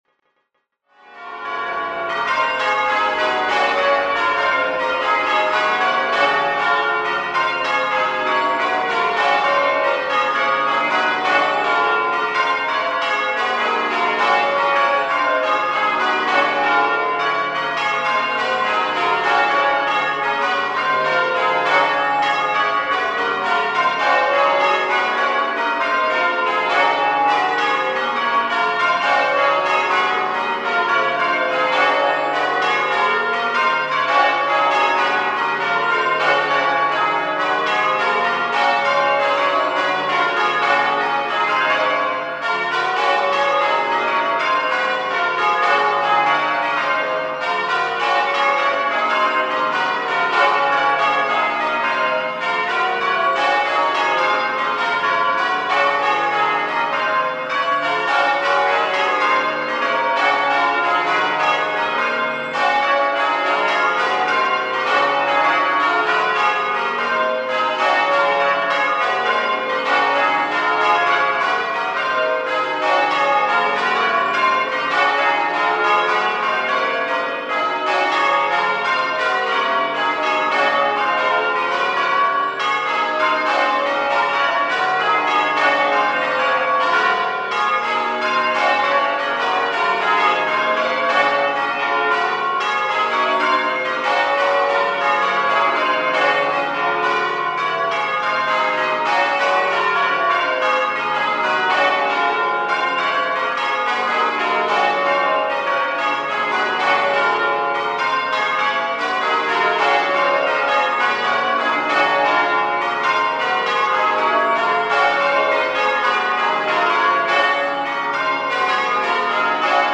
extract from a peal of Double Norwich Court Bob Major , 29 th June 2025, to mark the 500th anniversary of the completion of the building of the tower and the 400th anniversary of the casting of the tenor bell.